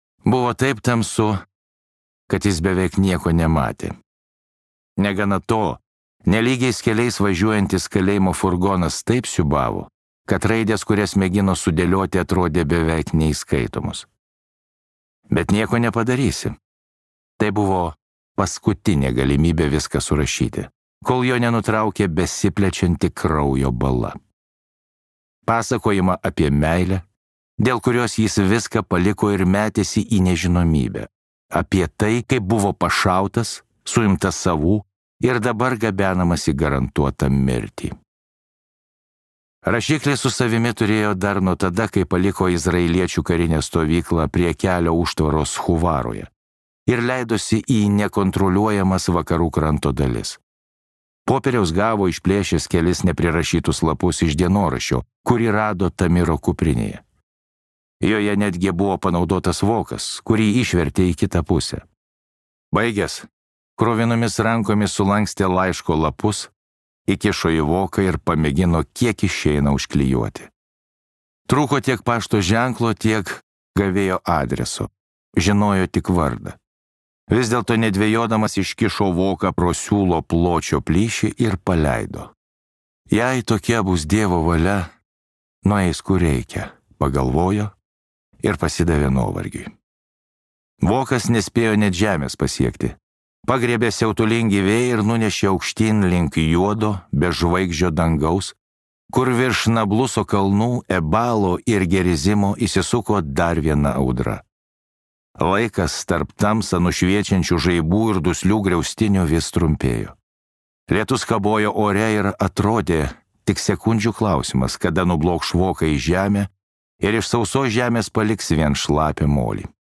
II dalis | Audioknygos | baltos lankos